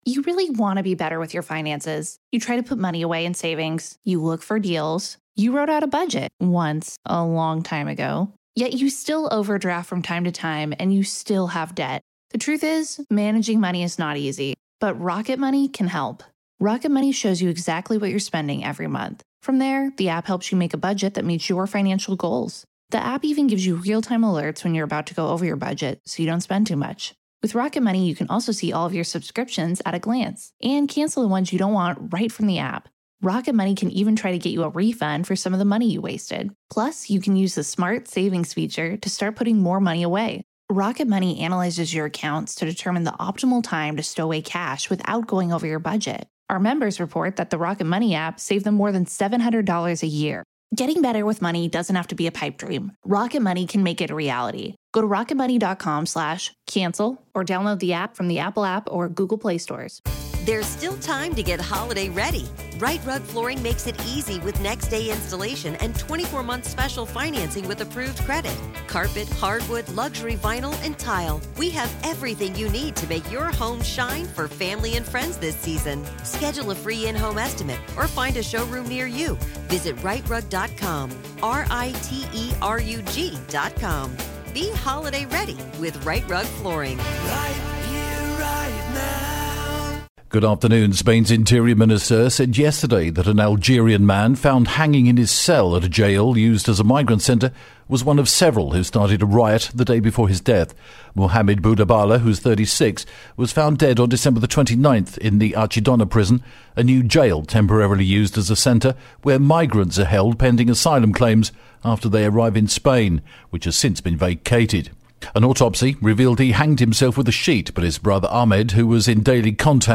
The latest Spanish News Headlines in English 25/01 pm